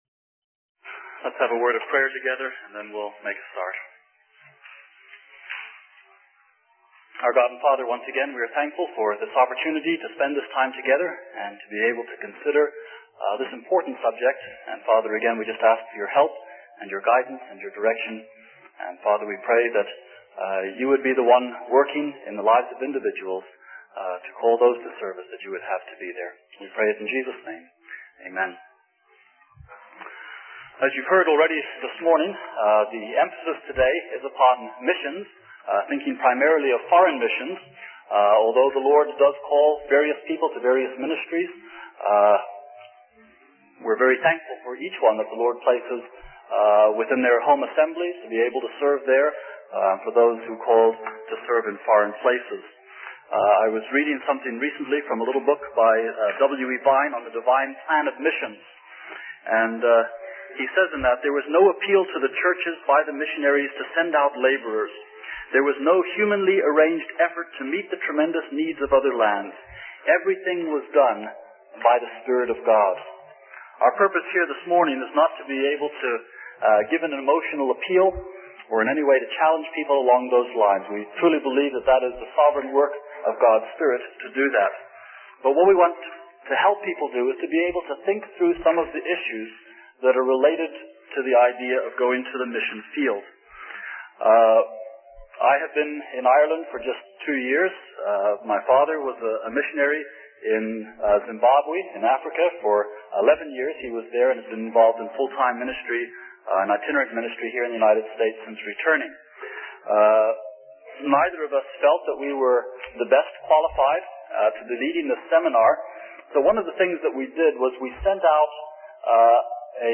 In this sermon, the speaker emphasizes the importance of recognizing that God is the one who leads and directs our plans. He discusses the call of God and how it may not always be a dramatic, audible experience, but rather a guiding and leading from the Lord. The speaker also highlights the significance of personal inventory evaluation and practical advice for potential missionaries.